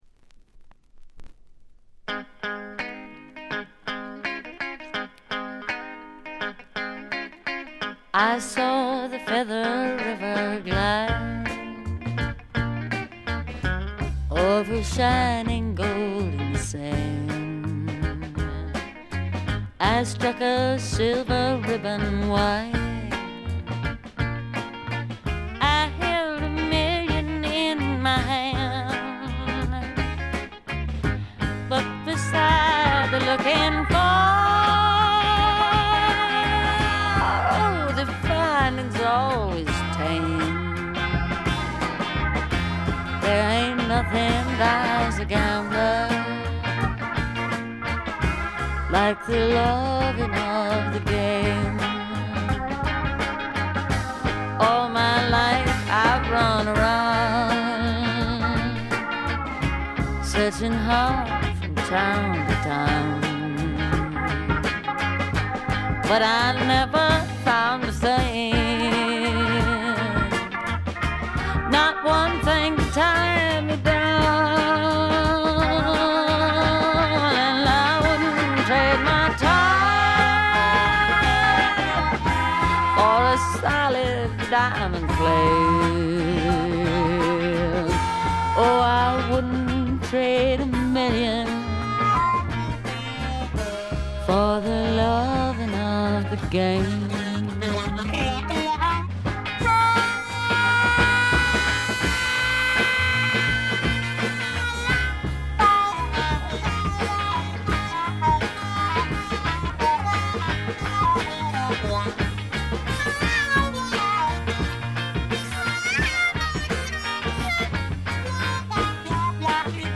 ほとんどノイズ感無し。
試聴曲は現品からの取り込み音源です。
Acoustic Guitar, Electric Guitar